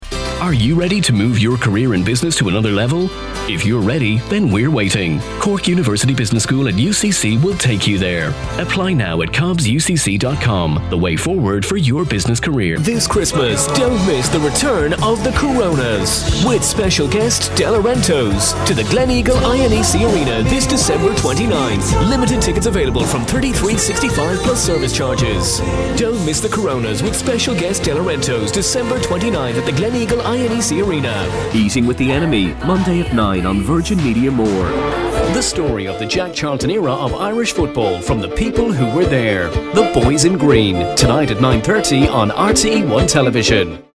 Male
20s/30s, 30s/40s
Irish Neutral